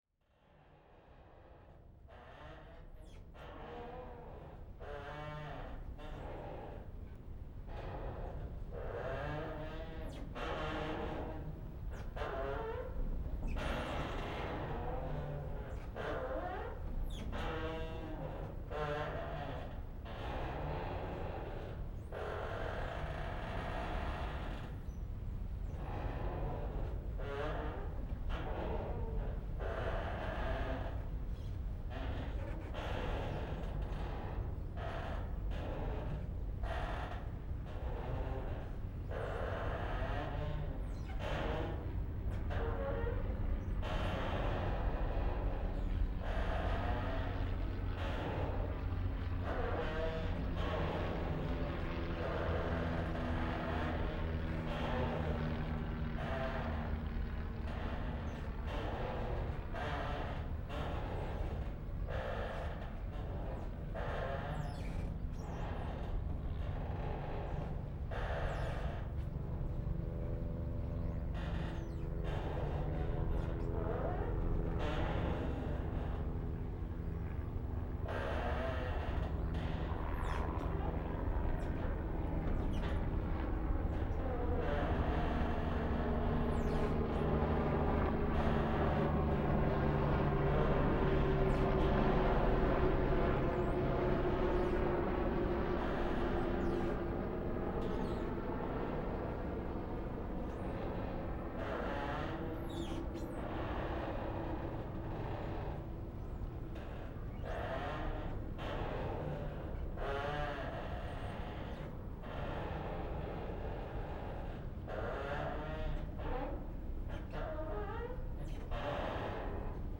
Copters'n'Creaks
This recording was made on a floating pontoon on the Hudson River near Newport-Pavonia PATH Station, Jersey City. The original intention was to record the creaks of the gangway as the pontoon moved up and down, but there was a lot of helicopter activity that day, due to a terrorist alert in Manhattan. The resultant recording has a nice sense of space and movement with some very good localization. The creaks should mainly come from behind and to the right of the listening position and the copters should pass above and in front.
Ambisonic order: F (4 ch) 1st order 3D
Microphone name: Soundfield ST250
Array type: Tetrahedral
Capsule type: subcardioid
jlcoptercreak_DTS.wav